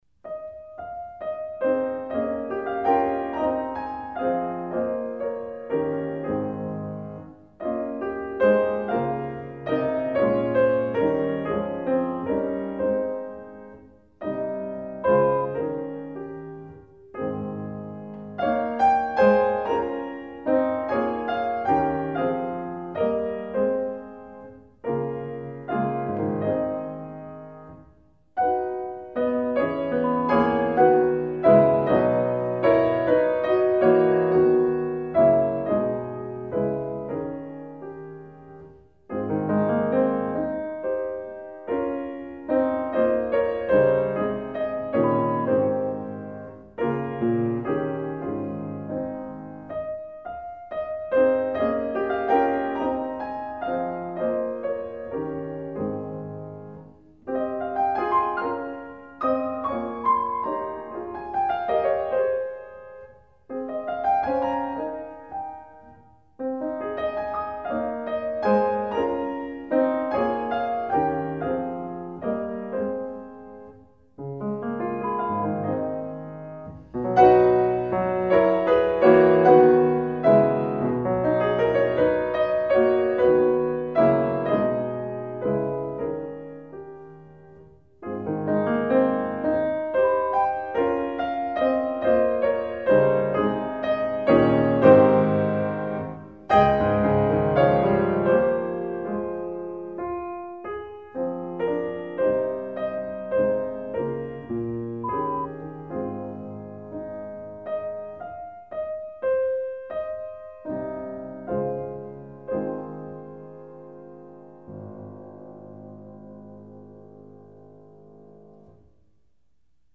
Recueil pour Soprano